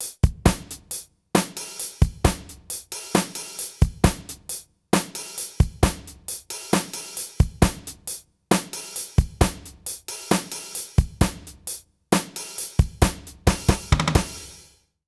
British ROCK Loop 134BPM.wav